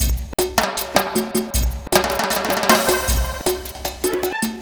Mambo 156-A.wav